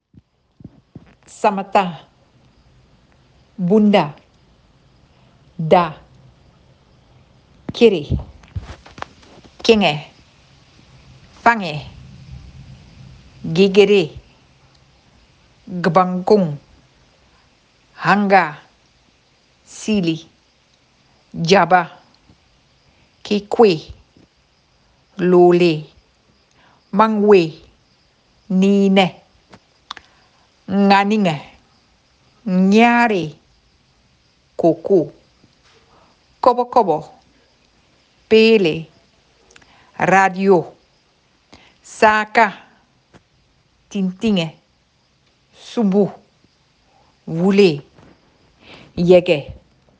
Uitspraak Mogofin alfabetkaart
Uitspraak-Mogofin-alfabetkaart.ogg